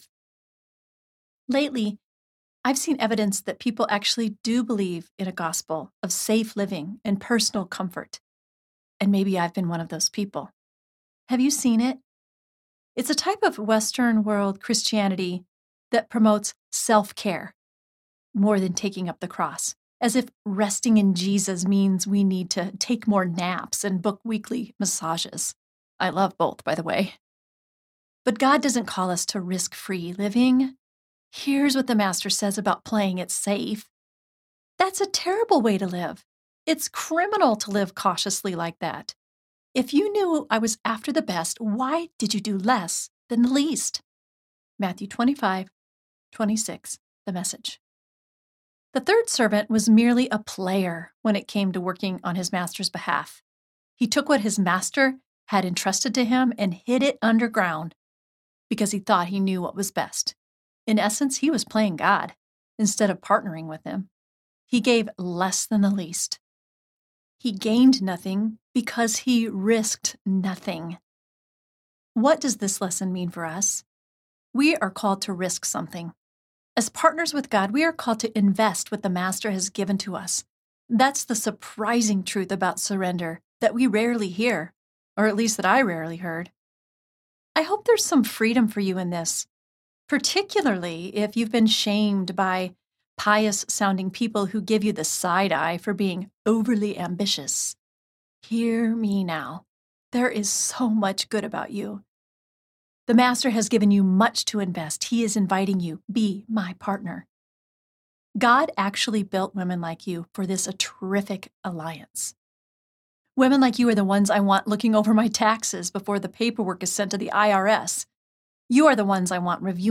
It’s All Under Control Audiobook